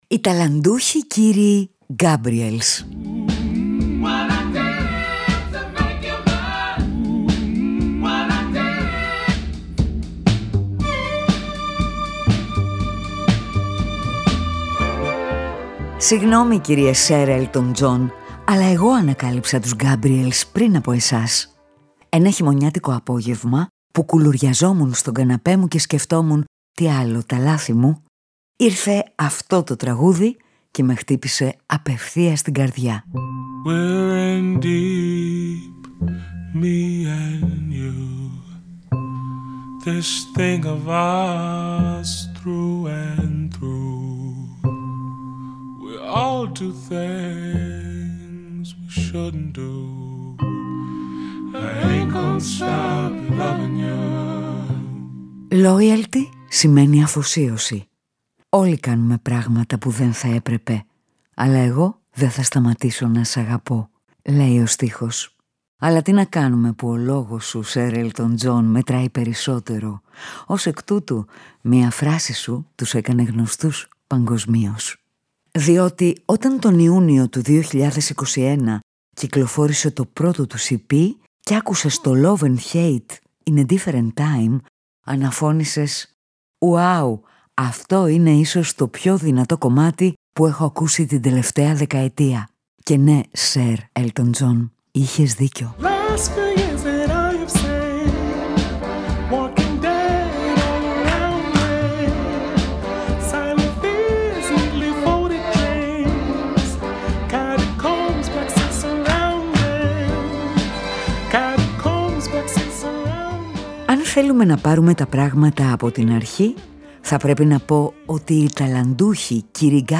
Τα τραγούδια τους άλλοτε θυμίζουν προσευχή κι άλλοτε μικρά, εξωστρεφή ξεσπάσματα. Έχουν συναίσθημα και χαρακτήρα.